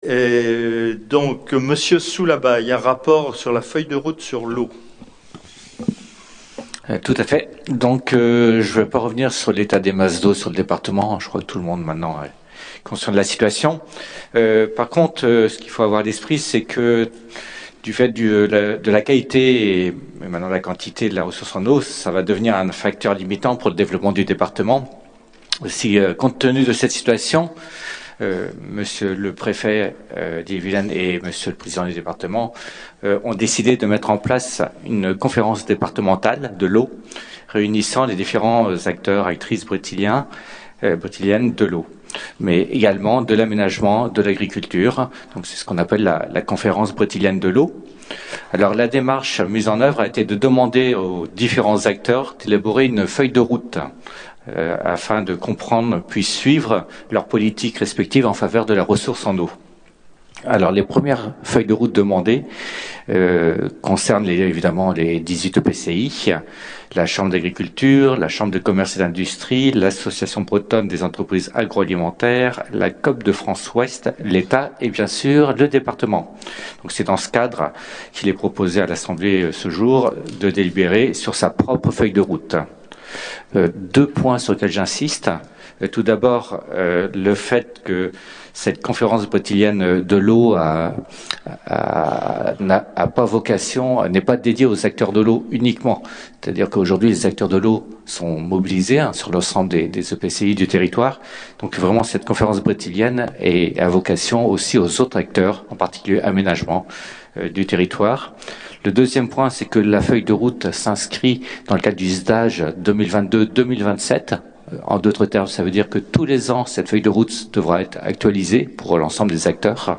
Environnement Nature Assemblée départementale